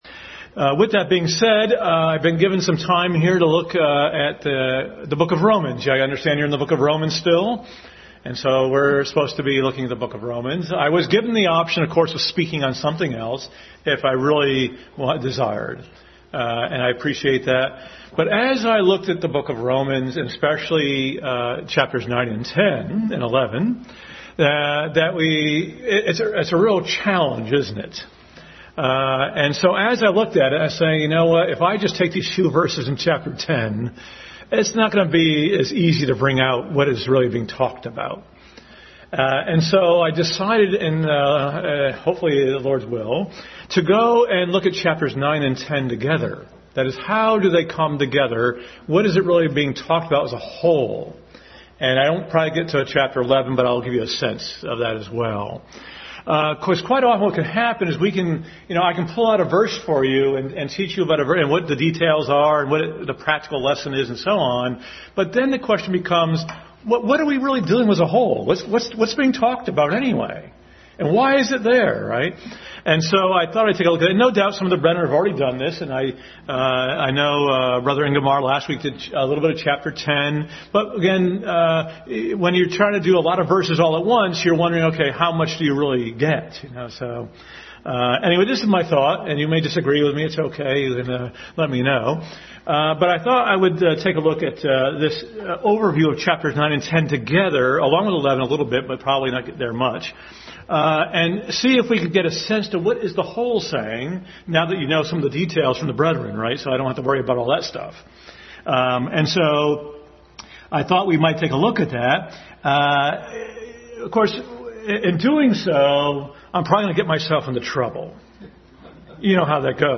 Adult Sunday School Class continued study of Romans.
Romans 10:1-8 Service Type: Sunday School Adult Sunday School Class continued study of Romans.